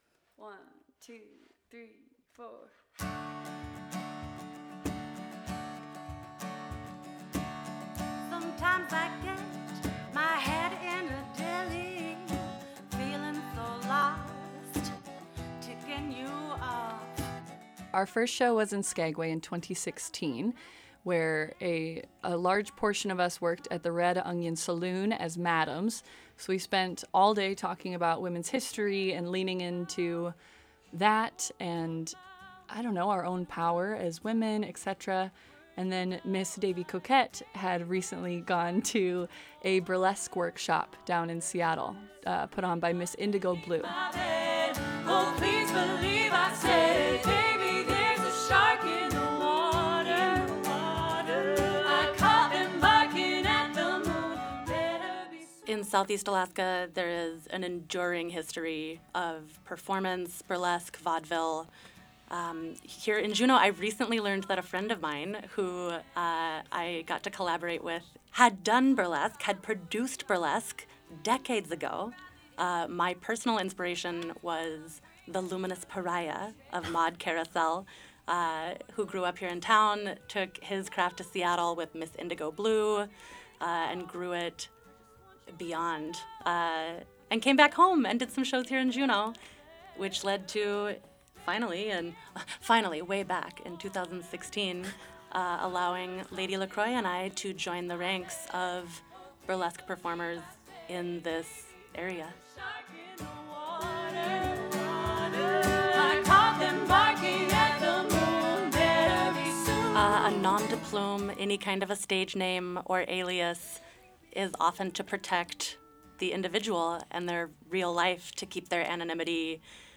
The Nude and Rude Revue performs in Studio 2K at KTOO.